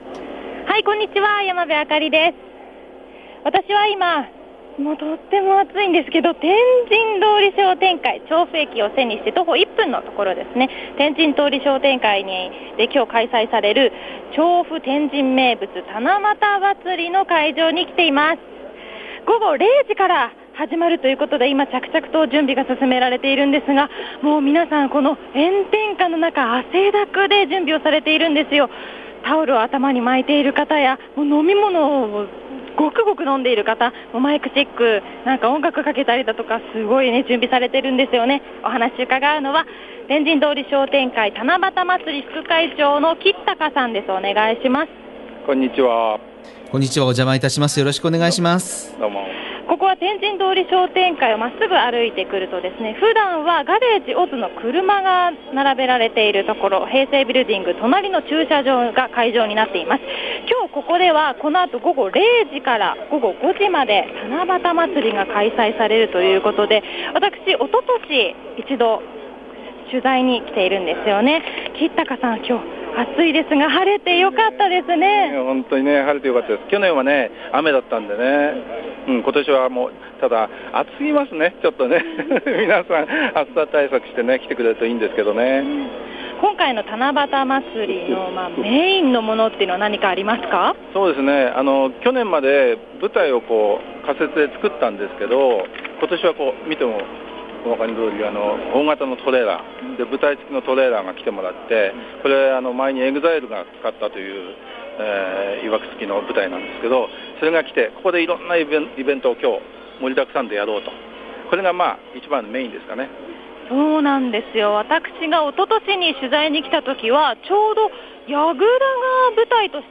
びゅーサン　街角レポート
今日お邪魔したのは、調布駅すぐ天神通り商店会の七夕祭り会場です。